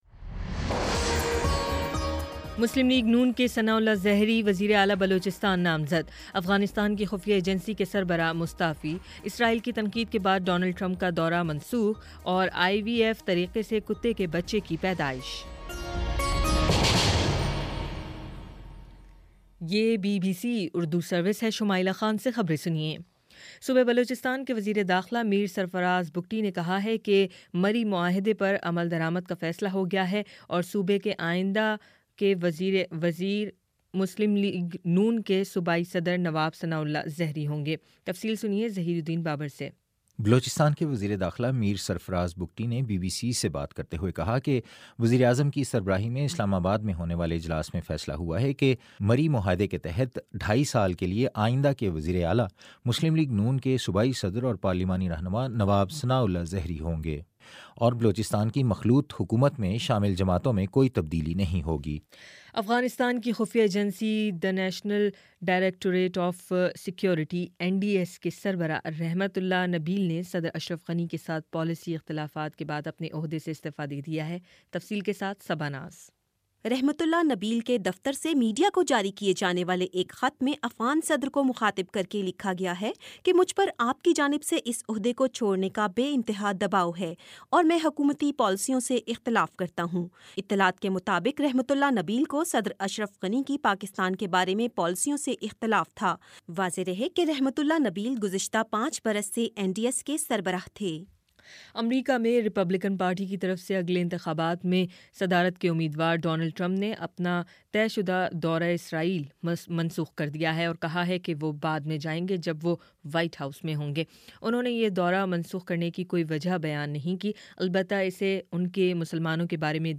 دسمبر 10: شام سات بجے کا نیوز بُلیٹن